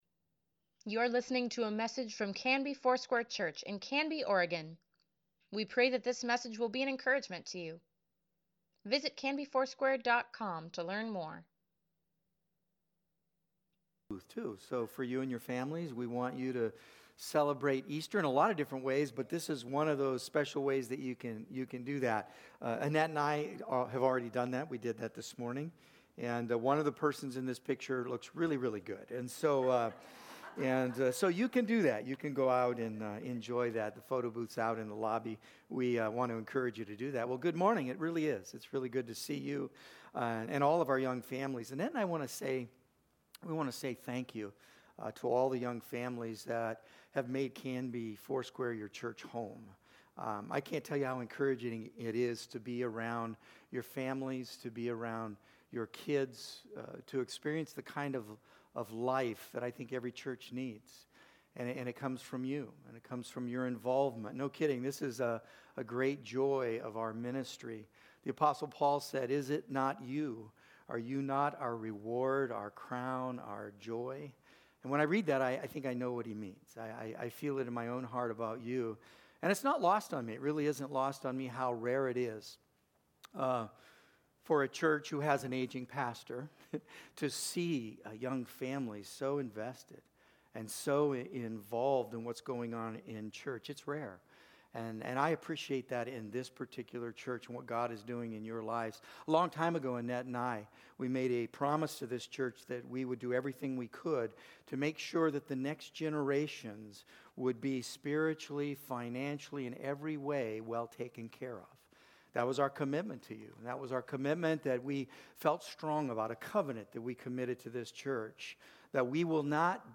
Sunday Sermon | March 24, 2024